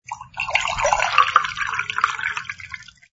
sfx_drinks_pouring01.wav